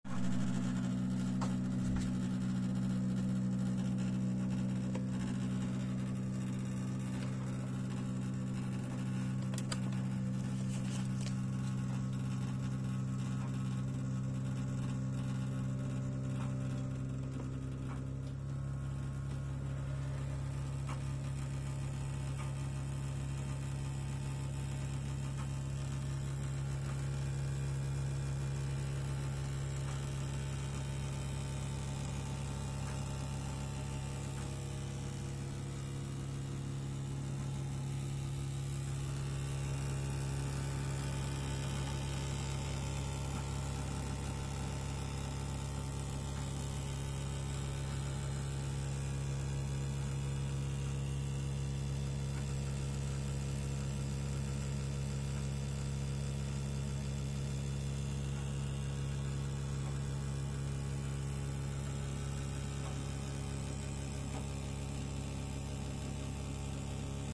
Il me semble que la vibration qui met tout en résonance provient du moteur d'extraction des gaz situés au-dessus de la chambre de combustion... mais comme tout finit par vibrer, ce n'est pas sûr du tout.
Un correspond aux vibrations entendues alors que la chaudière vient de se lancer pour le circuit de chauffage central.
Bruit chaudière mp3
bruit-chaudiere.mp3